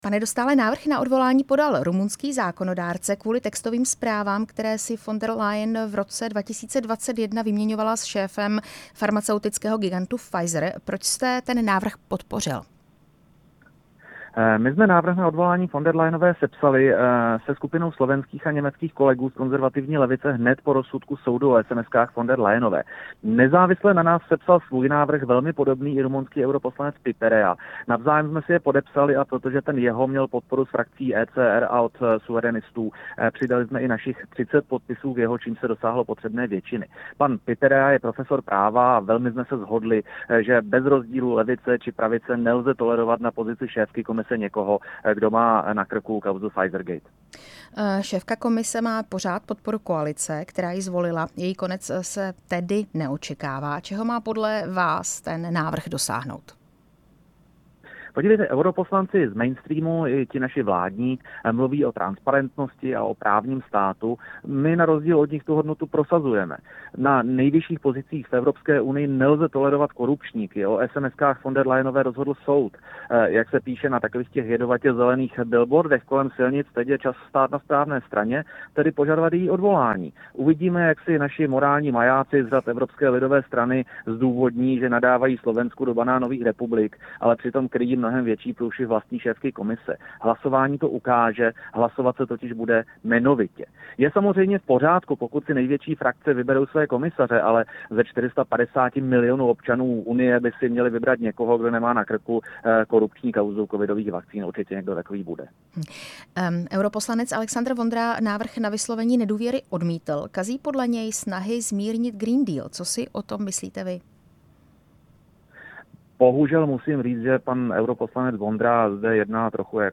Rozhovor s Ondřejem Dostálem z hnutí Staqčilo!